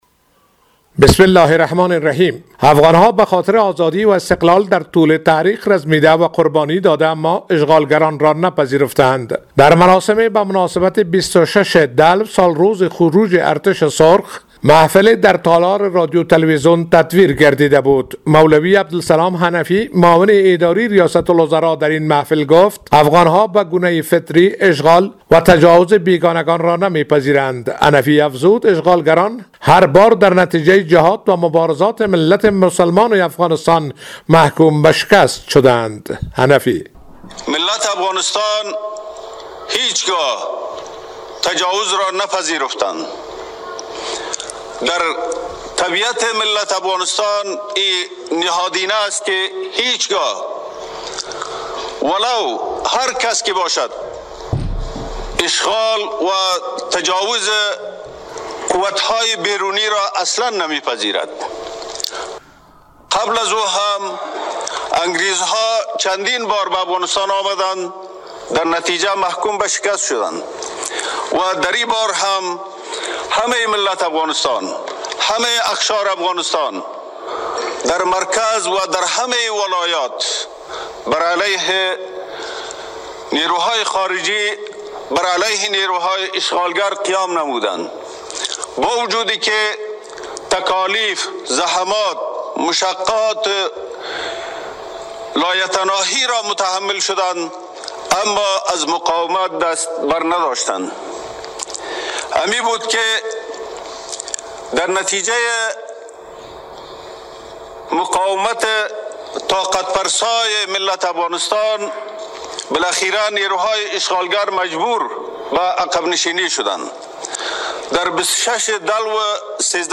حنفی در مراسم بزرگداشت ۲۶ دلو سالروز خروج ارتش سرخ شوروی از افغانستان افزود: افغان‌ها به‌صورت فطری اشغال و تجاوز بیگانگان را نمی‌پذیرند.